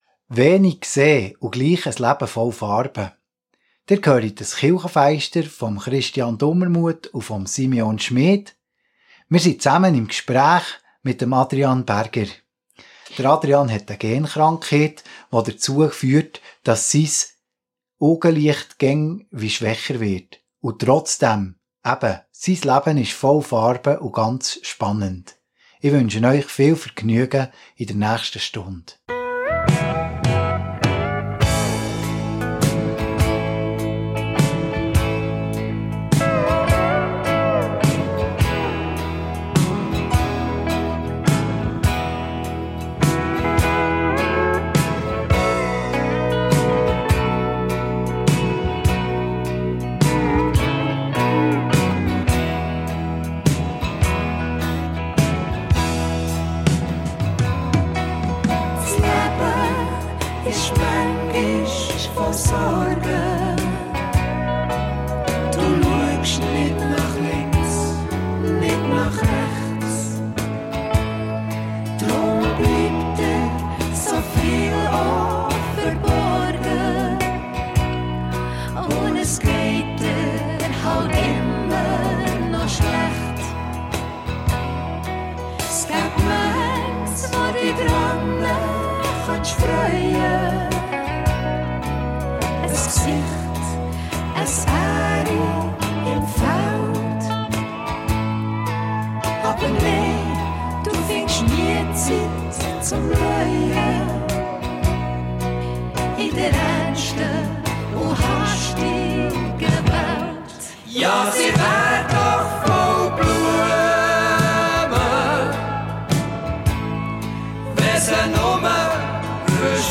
erzählt er im Gespräch